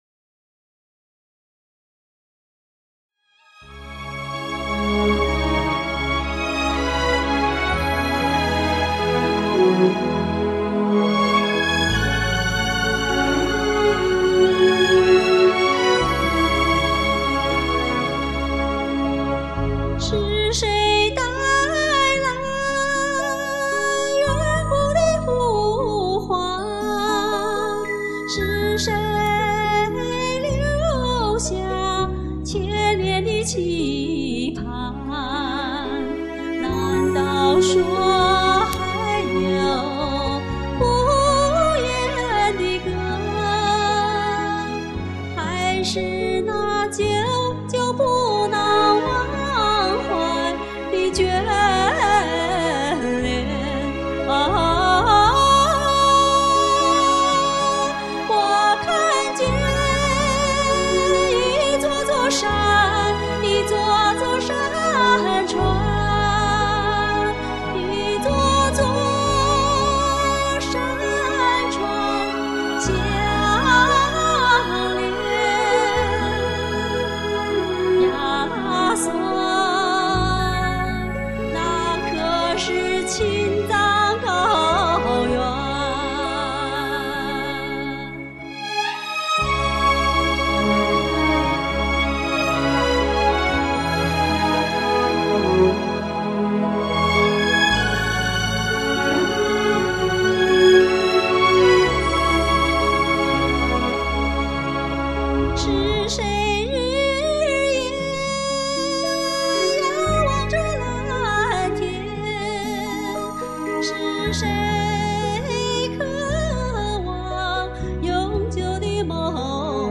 原汁原味、毫无装饰
感觉是那么清纯，不带修饰